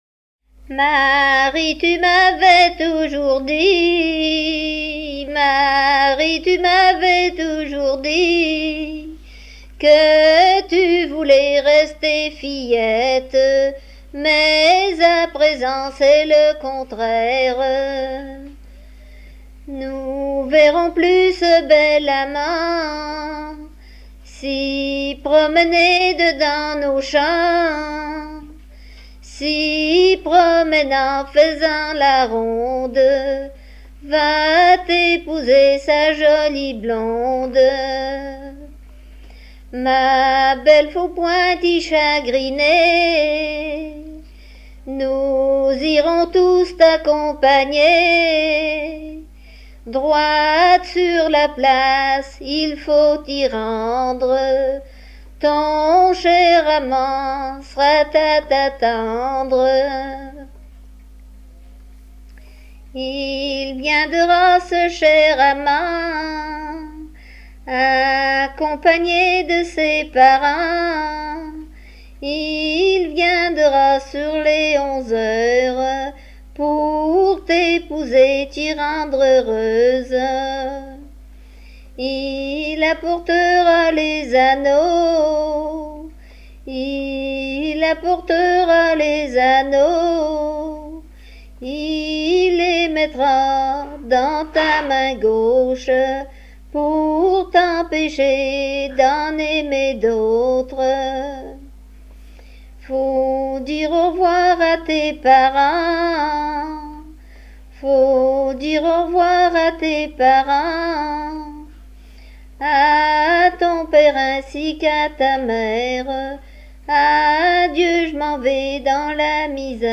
chant du départ de la mariée de sa maison
Saint-Christophe-du-Ligneron
circonstance : fiançaille, noce
Genre strophique